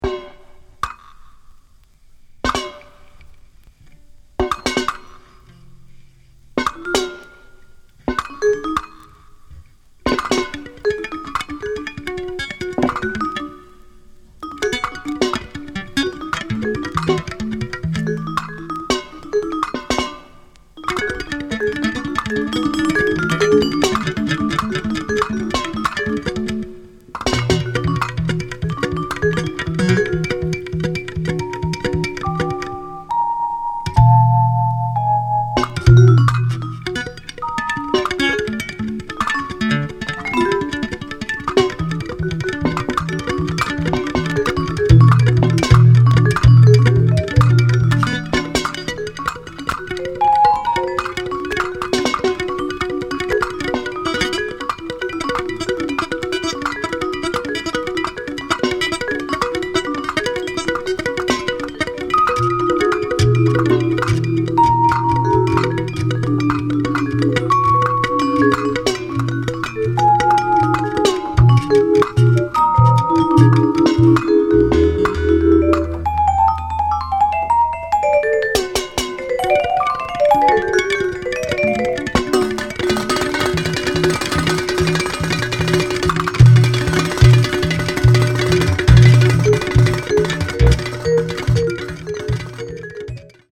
即興
ギター